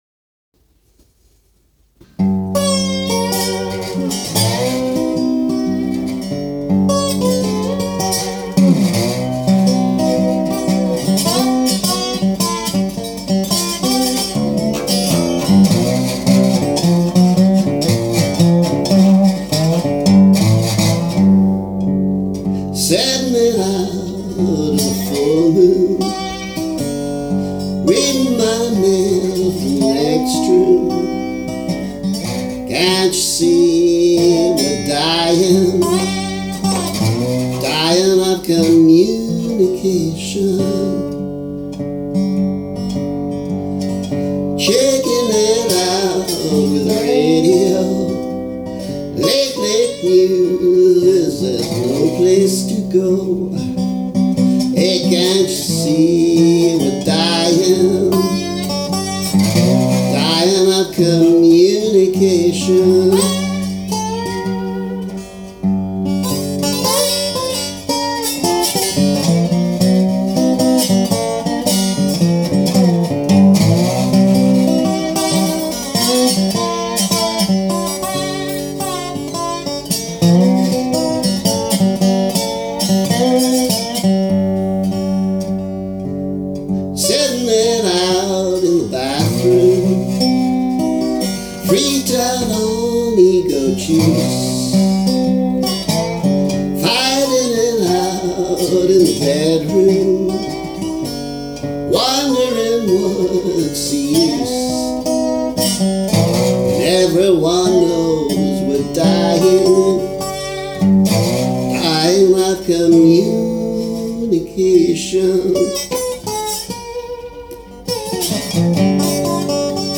Unusually (for me) the slide is an open G. I’ve been using an open C again recently, too.